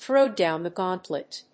アクセントthrów dówn the gáuntlet